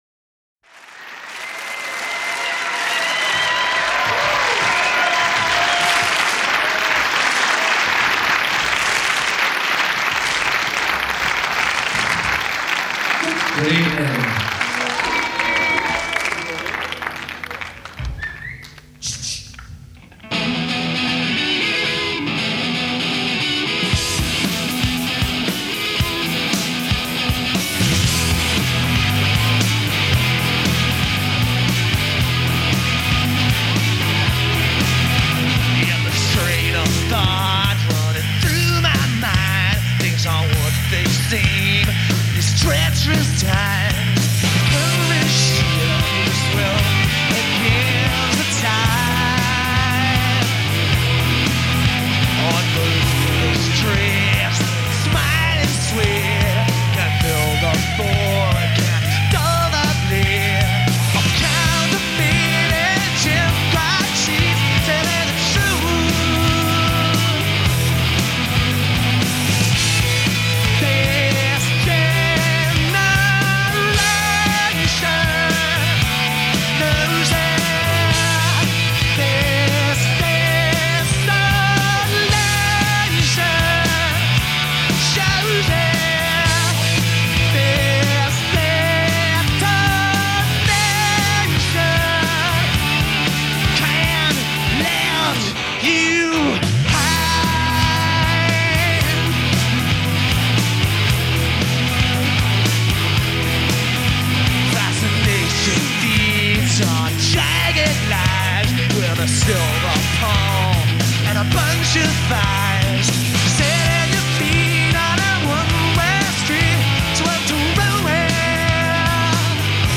enregistrée le 08/06/1993  au Studio 105